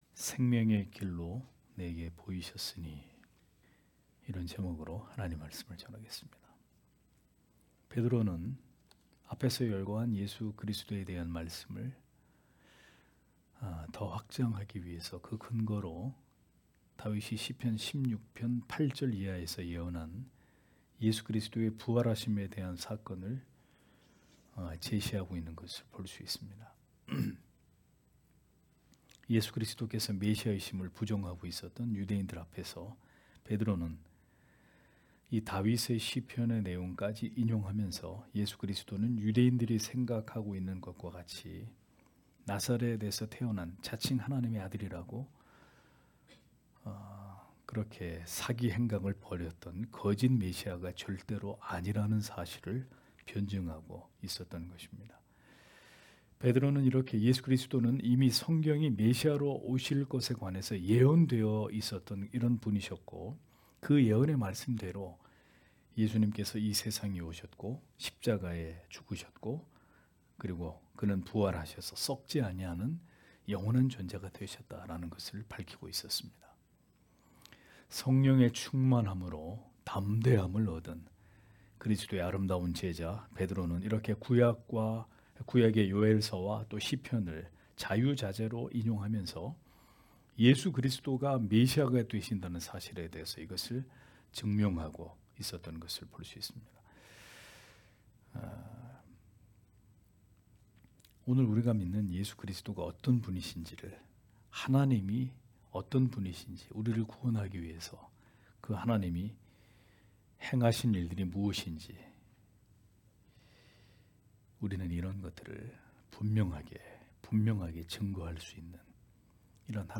금요기도회 - [사도행전 강해 14] 생명의 길을 내게 보이셨으니 (행 2장 25- 28절)